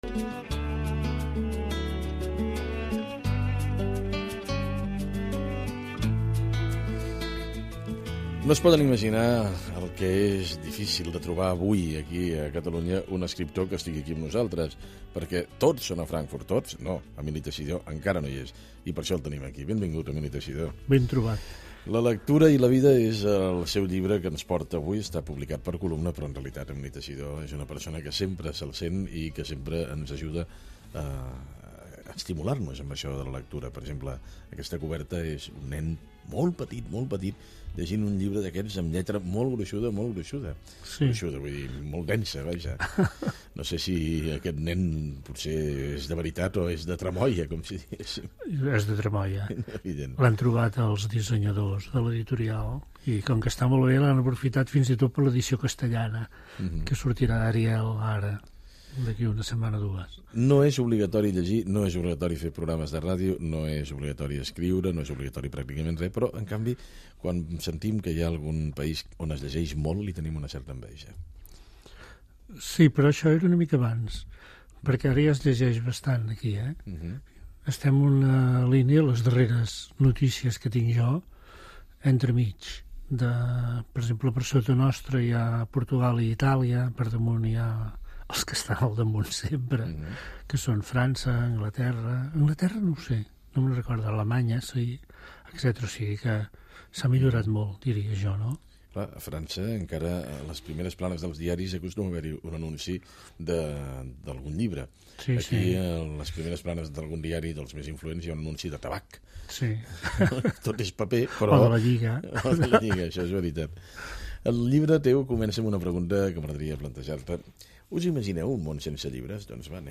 Entrevista a l'escriptor Emili Teixidor sobre el seu llibre "La lectura i la vida" i les seves primeres lectures Gènere radiofònic Informatiu